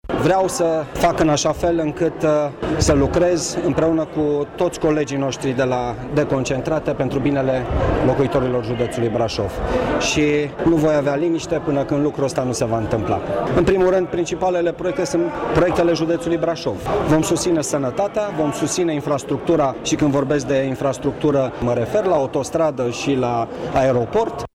În prezenţa primarilor din localităţile judeţului şi a reprezentanţilor instituţiilor deconcentrate, la Prefectura Braşov s-a desfăşurat festivitatea de depunere a jurământului de către noul prefect, Marian Rasaliu.
La început de mandat, prefectul Marian Rasaliu a spus că doreşte să susţină principalele proiecte ale judeţului: